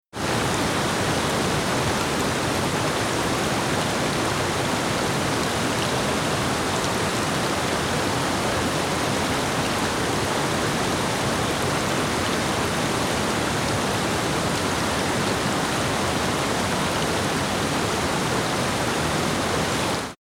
During their migration up the rivers of Southeast Alaska to spawn, salmon must navigate many challenging waters. This mighty waterfall is their biggest obstacle on this particular river. This recording captures the beautiful sound of this majestic water feature, with a small riffle in the foreground, and the deep rumble of the main waterfall in the background. Splashing salmon can occasionally be heard as well, as they search for a way to approach the difficult task at hand. This soundtrack is ideal for relaxation, meditation, or sleep.
Salmon-Waterfall-sample.mp3